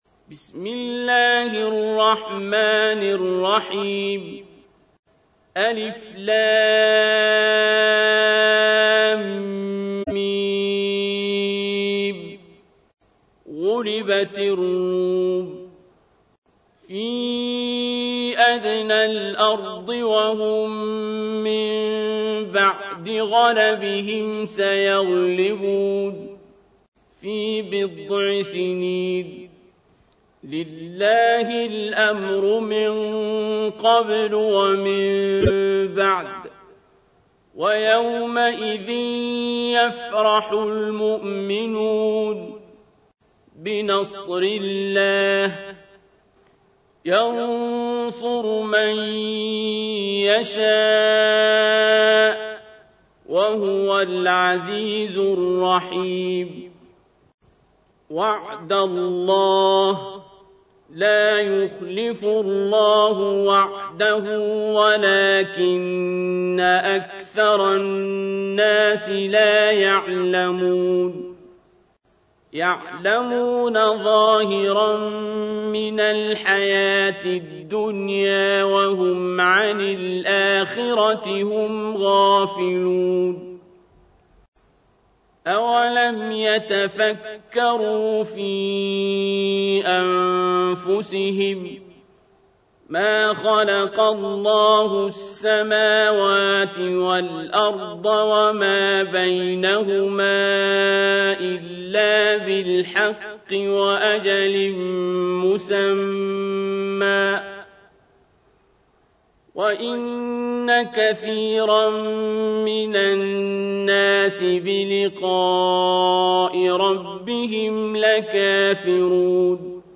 تحميل : 30. سورة الروم / القارئ عبد الباسط عبد الصمد / القرآن الكريم / موقع يا حسين